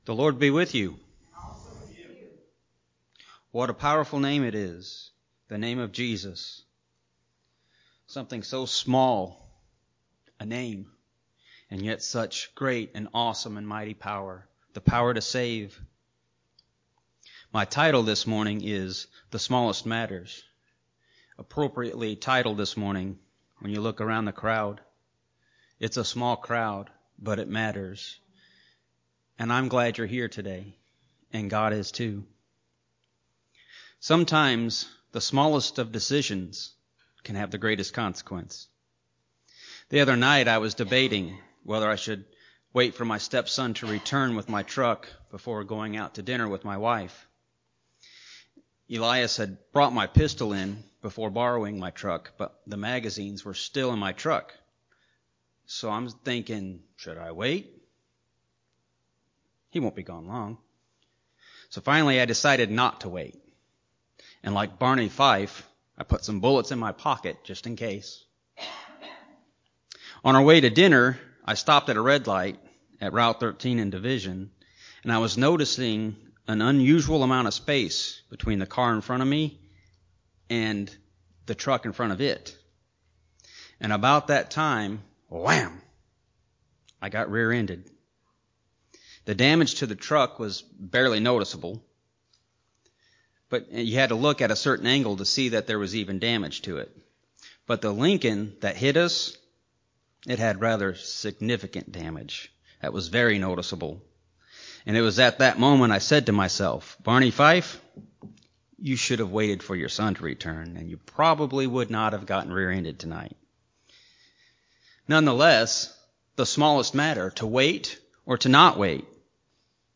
In this message delivered by one of our deacons, we are reminded that even the smallest things matter!